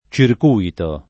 ©irk2ito] s. m. («giro») — domin. oggi tra le persone cólte, e tanto più in moderni usi specifici (tecn., econ., sport.), la pn. ©irk2ito, etimologica (dal lat. circuitus con -i- breve): vincitori di corse in circùito [vin©it1ri di k1rSe in ©irk2ito] (D’Annunzio); e così senz’altro in composti di formaz. moderna come cortocircuito e microcircuito (sec. XX) — dovuta all’attraz. dei part. pass. in -ito la vecchia pn. ©irku-&to, prob. in Dante (Or perché in circuito tutto quanto L’aere si volge [